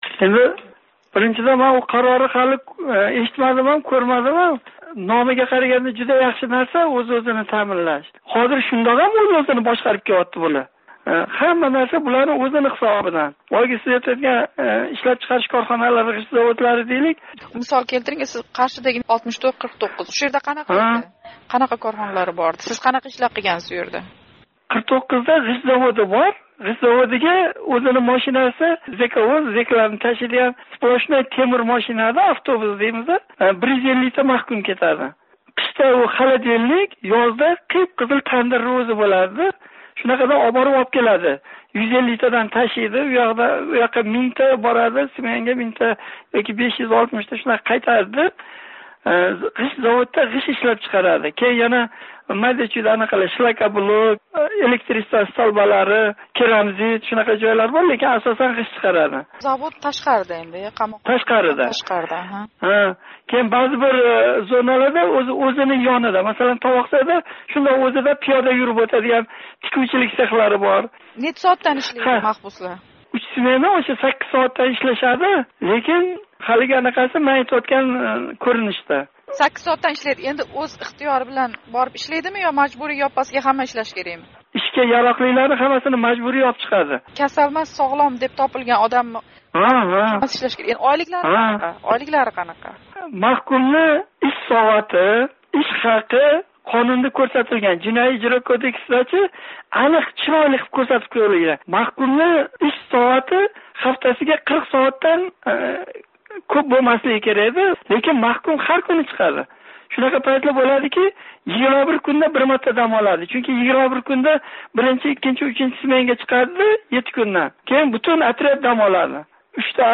суҳбат